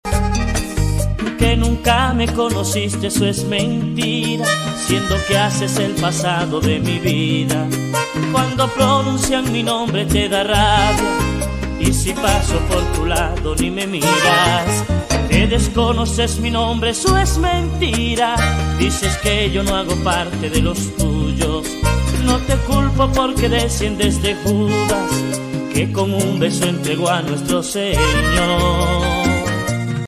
vallenatos